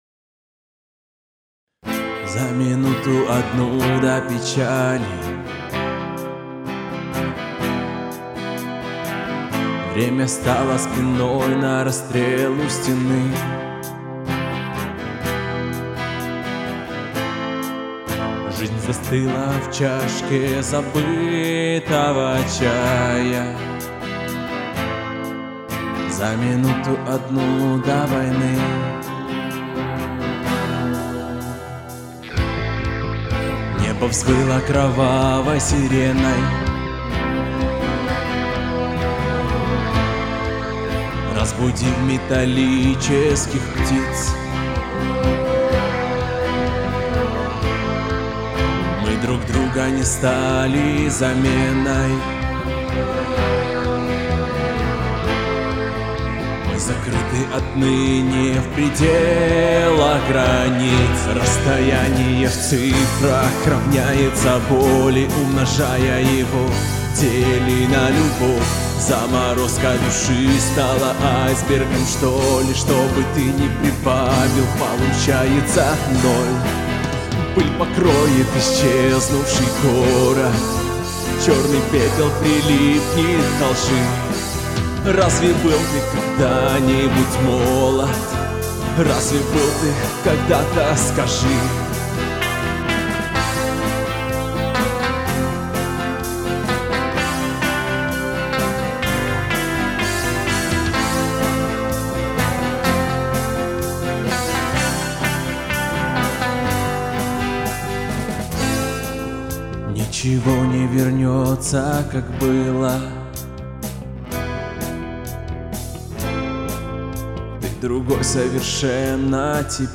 256 просмотров 449 прослушиваний 10 скачиваний BPM: 126